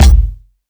KICK.28.NEPT.wav